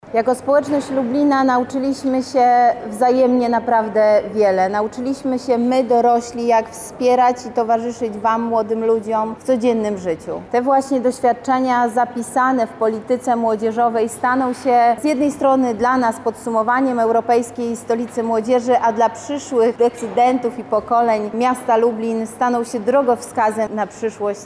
• mówi Beata Stepaniuk-Kuśmierzak, zastępczyni prezydenta Lublina.